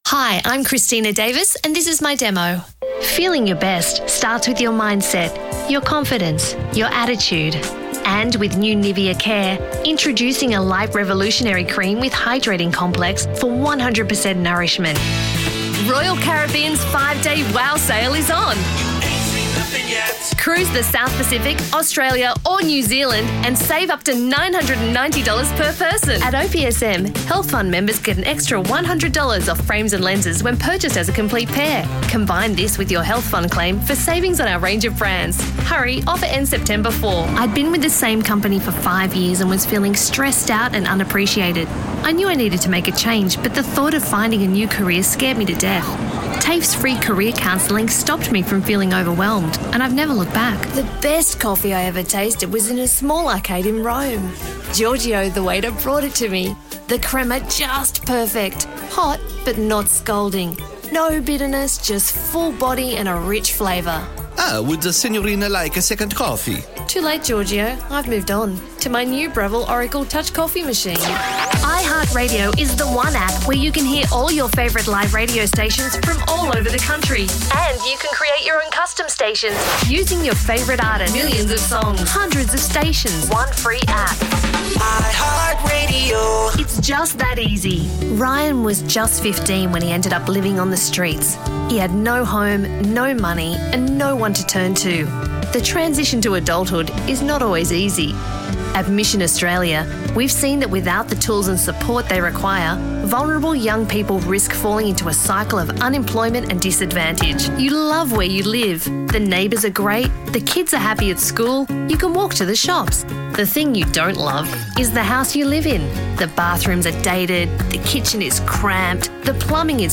Professional Voiceover Artist
Voice Over Demo
This audio sample features a range of styles from commercial voiceovers to informative narration, showcasing my ability to adapt to various tones and industries.